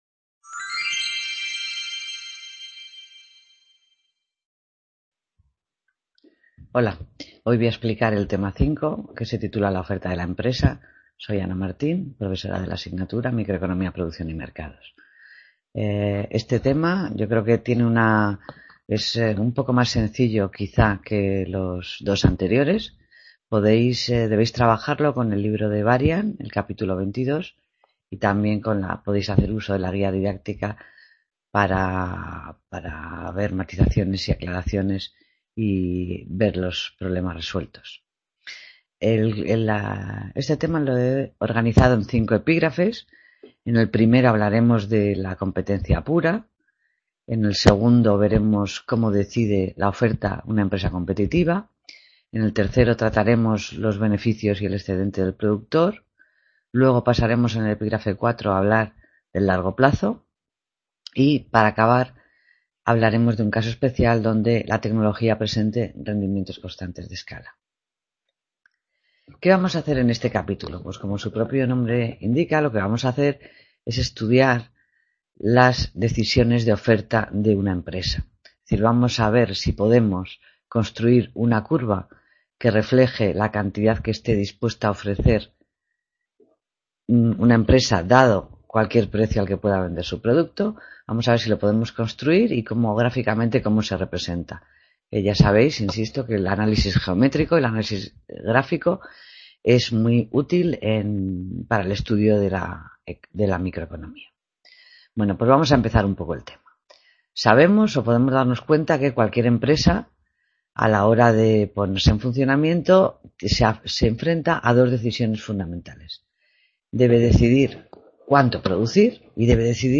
Clase Tema 5: la oferta de la empresa | Repositorio Digital